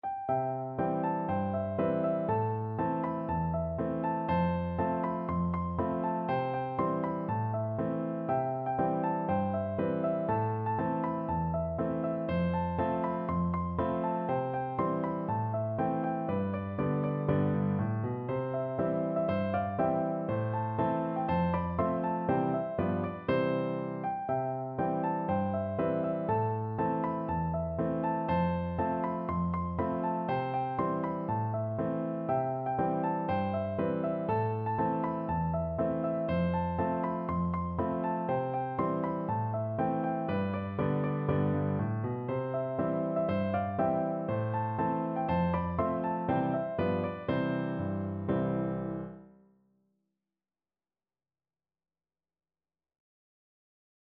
C major (Sounding Pitch) (View more C major Music for Piano Duet )
2/2 (View more 2/2 Music)
Piano Duet  (View more Easy Piano Duet Music)
Traditional (View more Traditional Piano Duet Music)